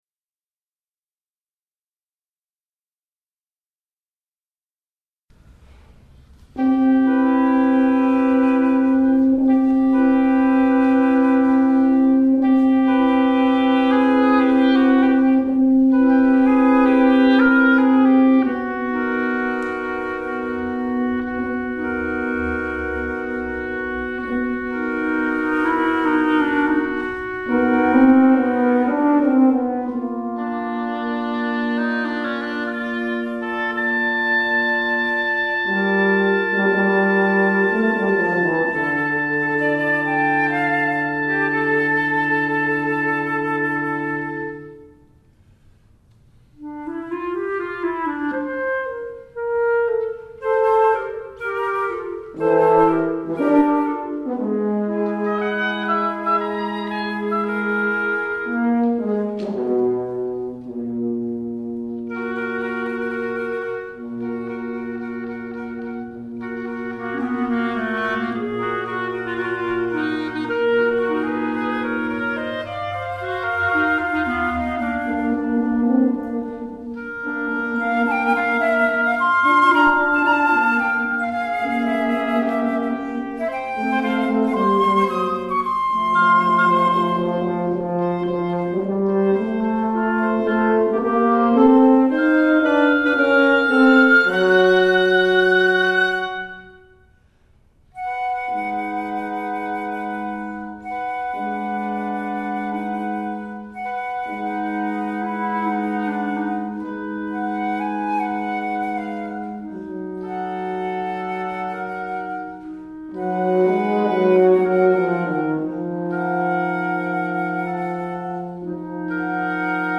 Winds/Chamber